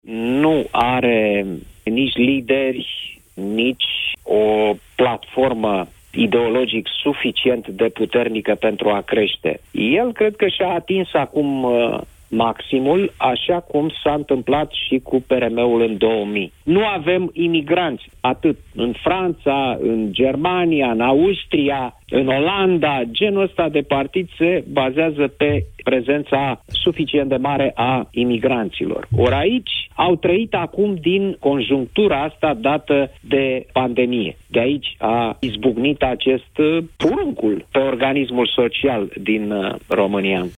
El nu are cum să fie mai mult de un partid minoritar în Parlament, a spus Cristian Tudor Popescu în emisiunea Deşteptarea: